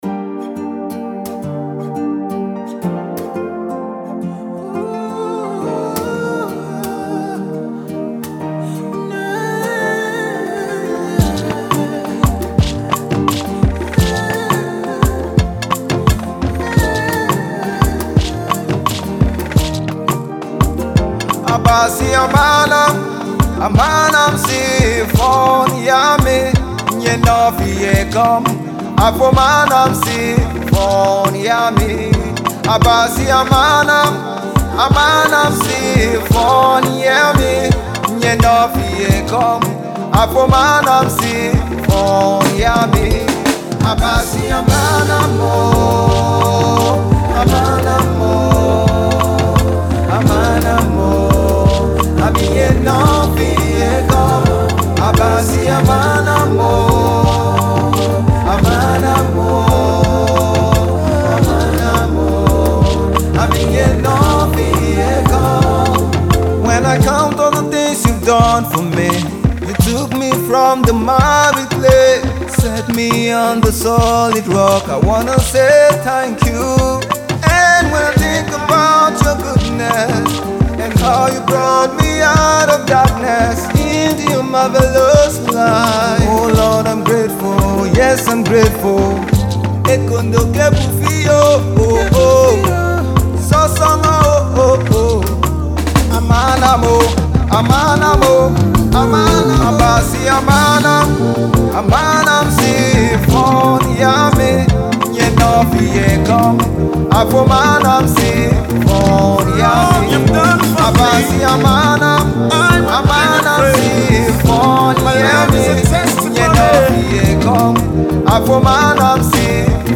is a thanksgiving song